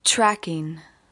描述：语音编辑，女性对狼
Tag: 音响设计 悬疑 恐怖 SFX 工作室 可怕